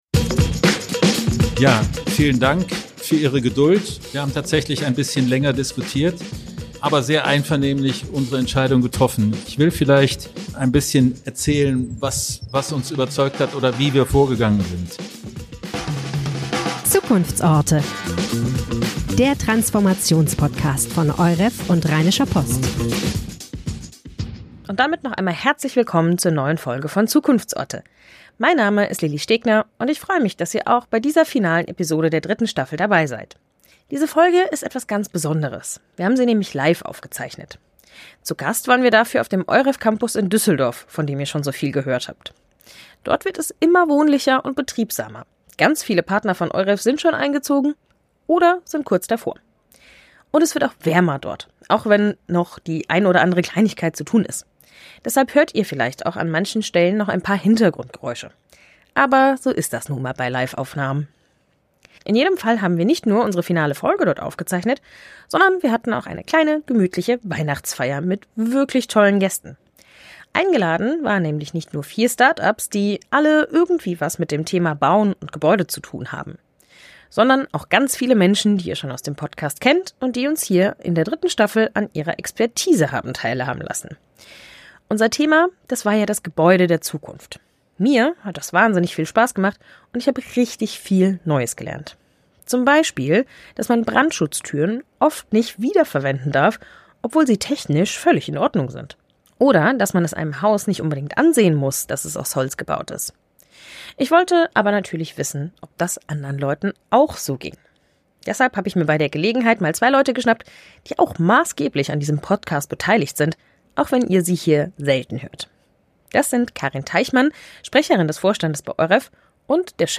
Einige stellen sich live bei Zukunftsorte vor.